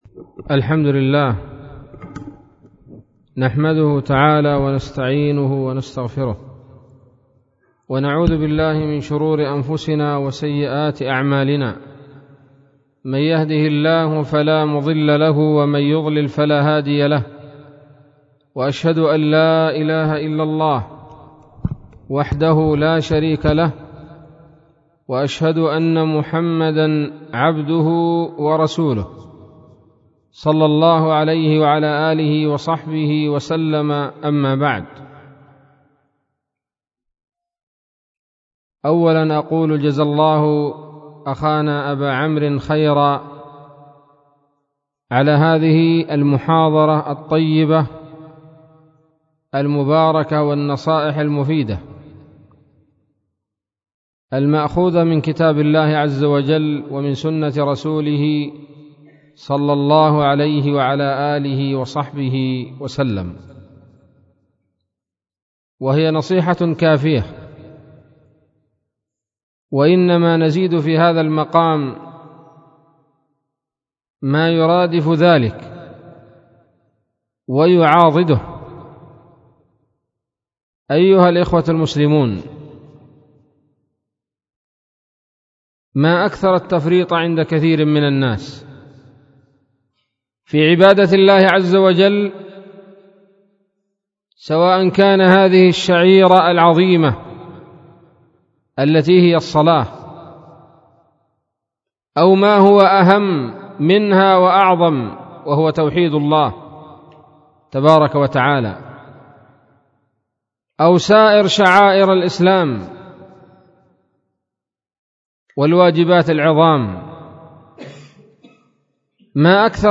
محاضرة بعنوان :((ألا نتوب؟!)) 10 ربيع الأول 1438 هـ